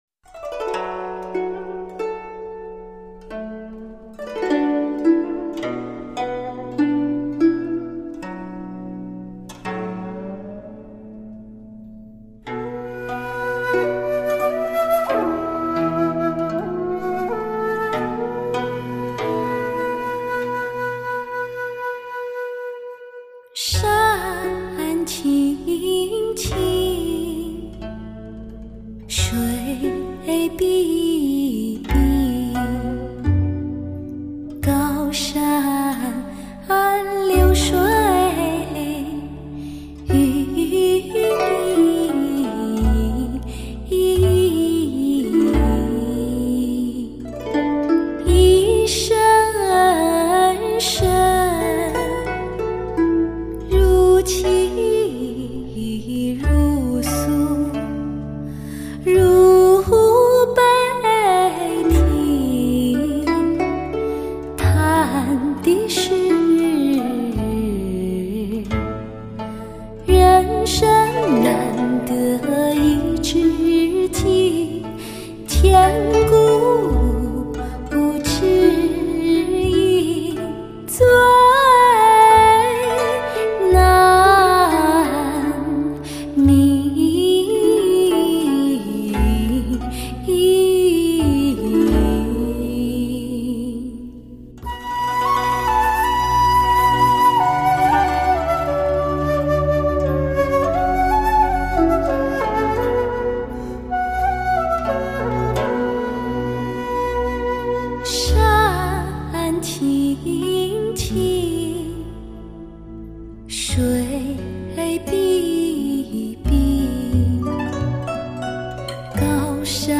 唱片类型：民族声乐
两位歌手甜美的歌声令人陶醉